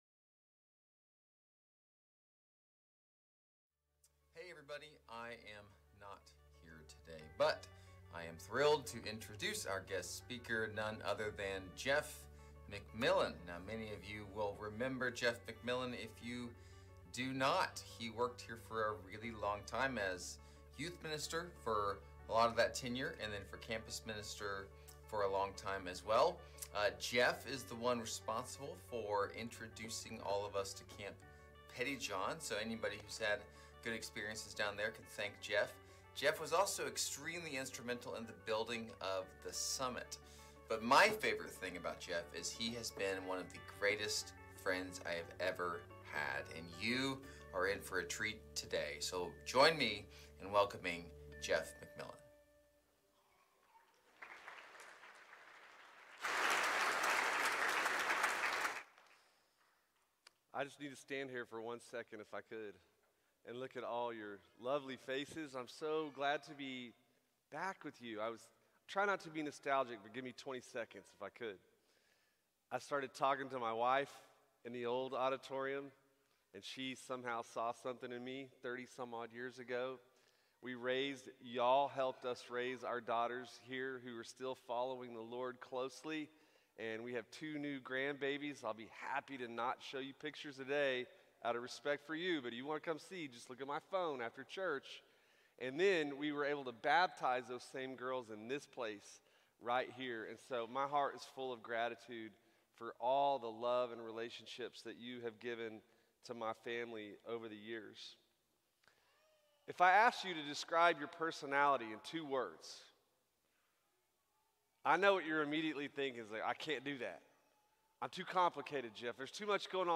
Series: Stand Alone, Sunday Morning